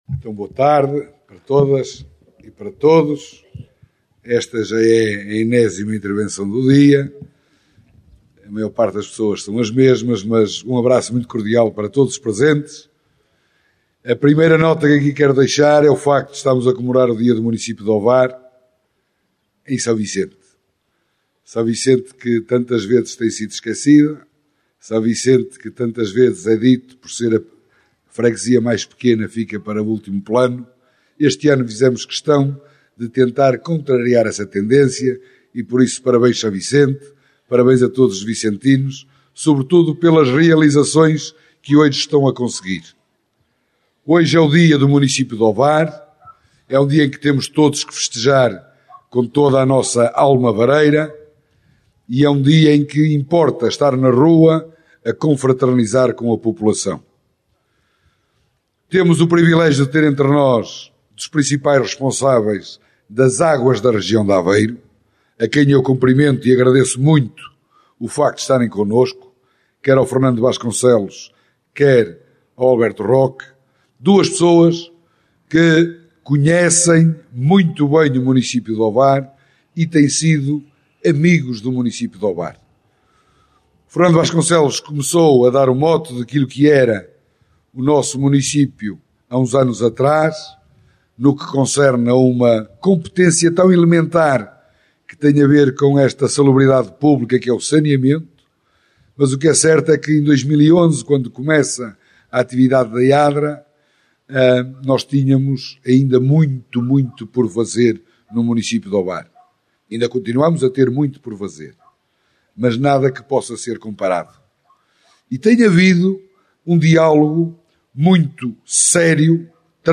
O último ato público decorreu em S. Vicente de Pereira Jusã, com a Inauguração do Parque de Lazer e Bem-Estar e com a Apresentação do Projeto “Águas Residuais do Sistema de S. Vicente de Pereira (PAR – 100) – 2ª Fase A – Ovar”, uma obra da Águas da Região de Aveiro (AdRA).
Salvador Malheiro | Câmara Municipal de Ovar